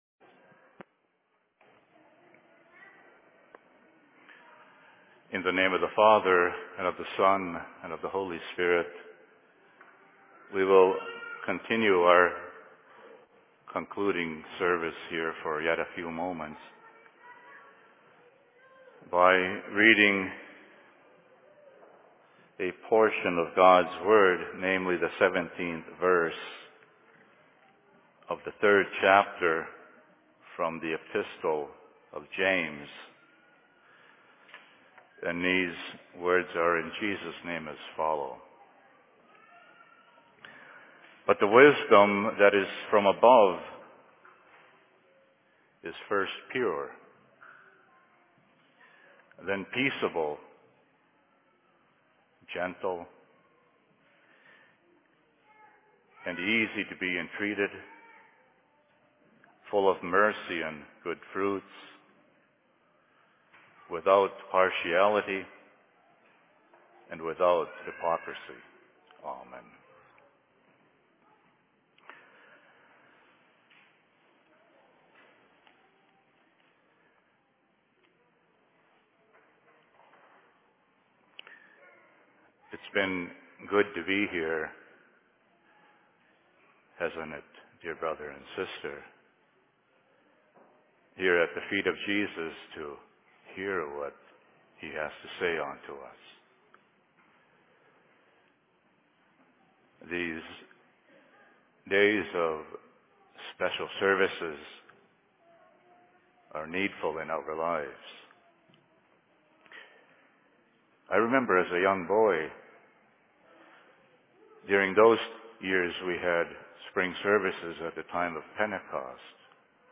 Spring Services/Sermon in Cokato 05.05.2013
Location: LLC Cokato